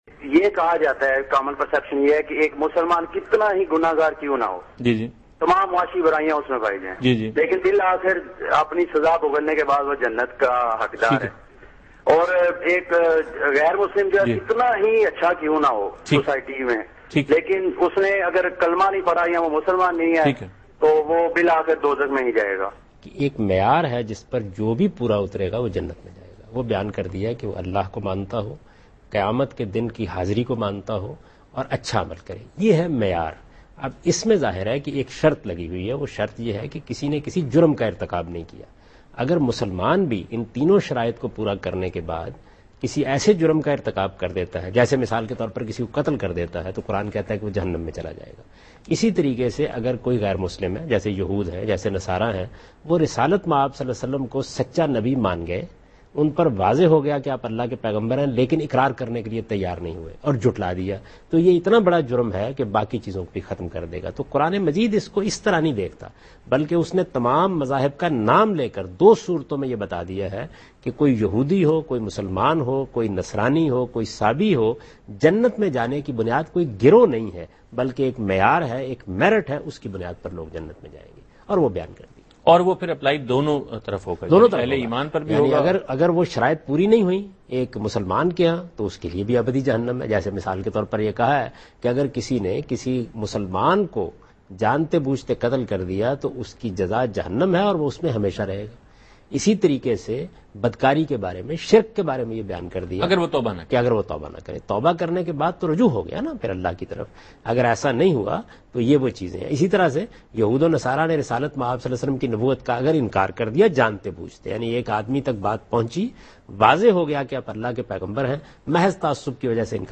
Category: TV Programs / Dunya News / Deen-o-Daanish /
Javed Ahmad Ghamidi addresses this question in program Deen o Daanish on Dunya News.